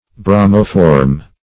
Bromoform \Bro"mo*form\, n. [Bromine + formyl.] (Chem.)